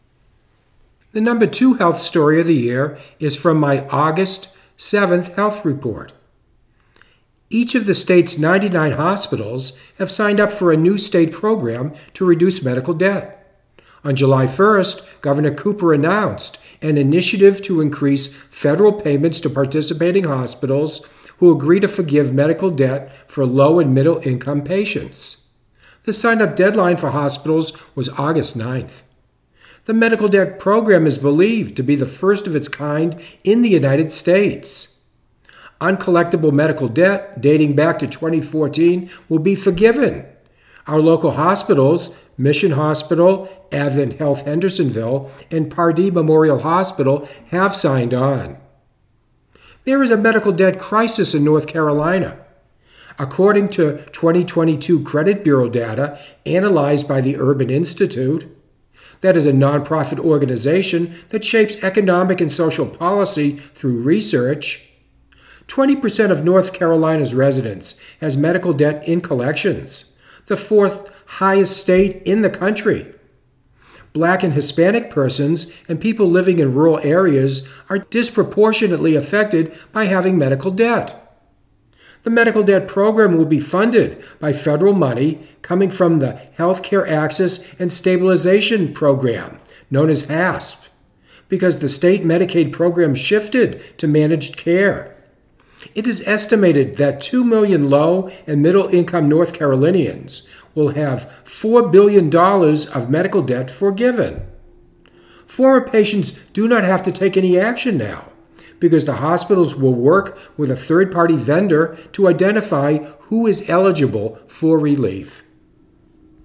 #2 Health report for 2024: Every hospital in North Carolina hospital will forgive medical debt